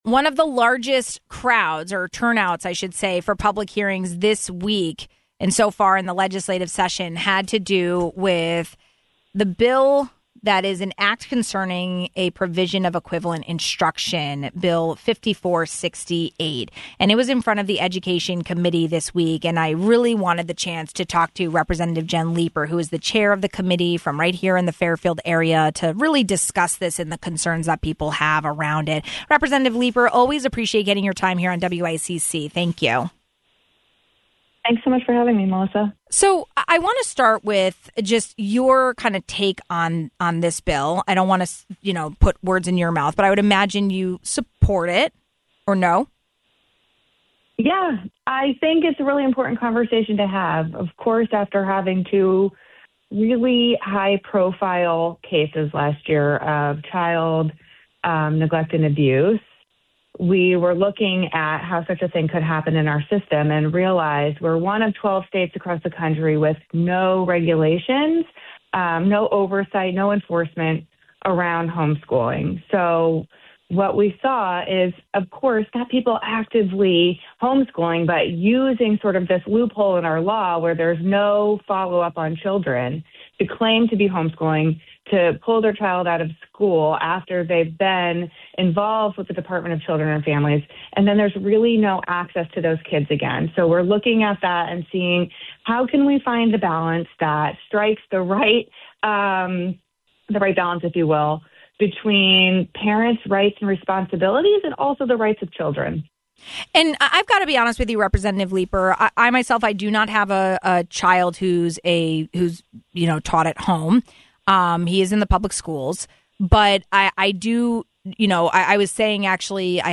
One of the largest public hearing turnouts happened this week for House Bill 5468: An Act Concerning the Provision of Equivalent Instruction. We did a deeper dive into it with State Representative Jen Leeper, Chair of the Education Committee.